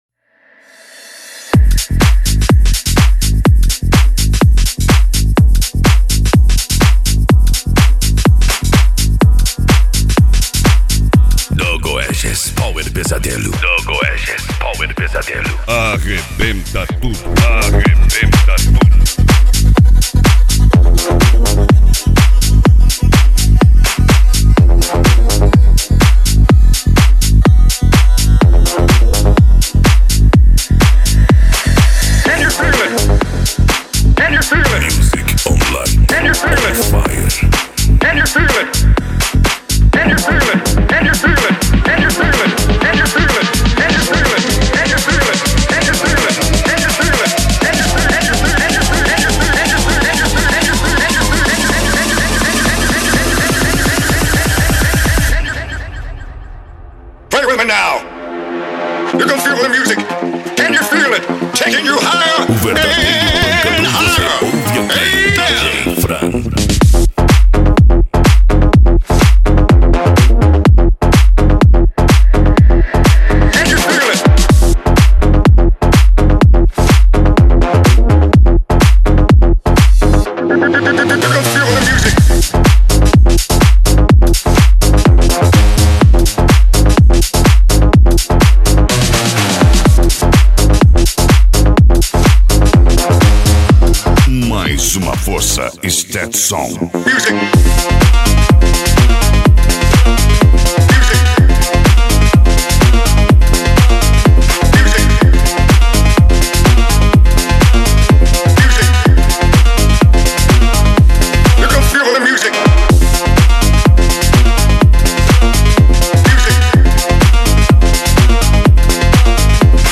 Musica Electronica
Psy Trance
Remix
Techno Music
Trance Music